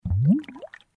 Звуки бульканья